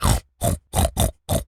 pig_sniff_03.wav